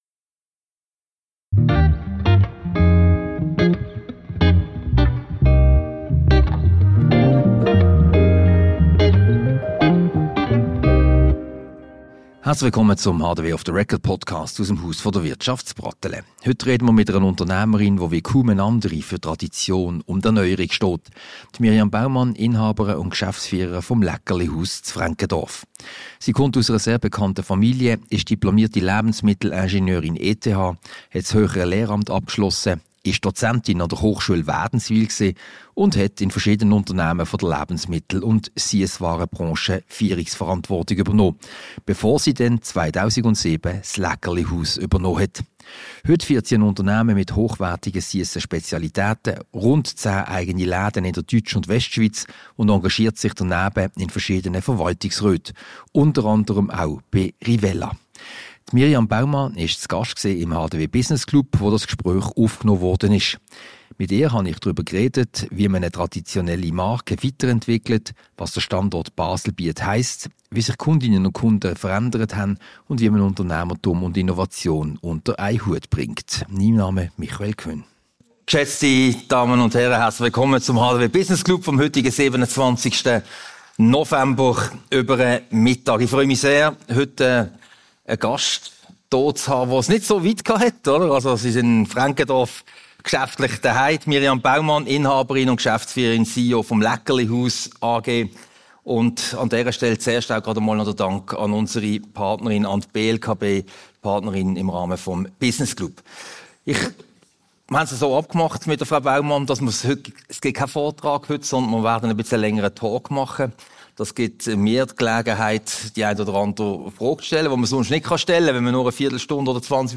Diese Podcast-Ausgabe wurde anlässlich des HDW-Business-Club-Lunches vom 27. November im Haus der Wirtschaft HDW aufgezeichnet.